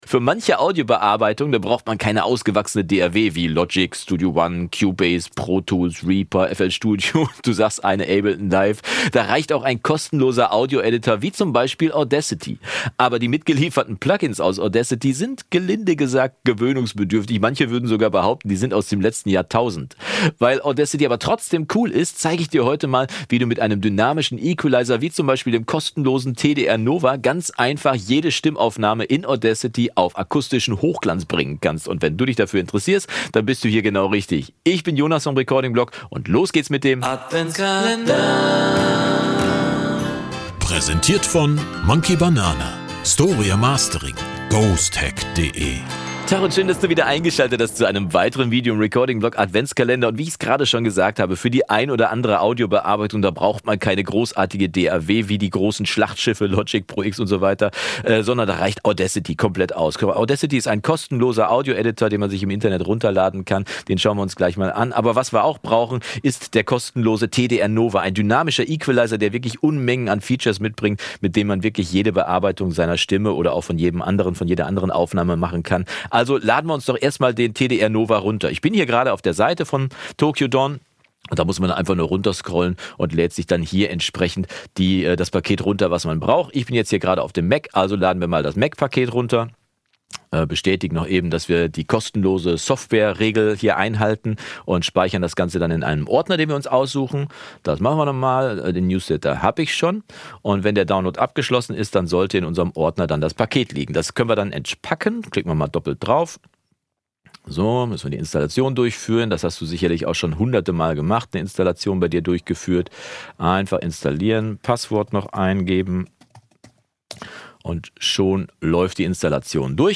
Top: Gratis Stimme in Audacity mit TDR NOVA bearbeiten | Tutorial | Recording-Blog AK18-21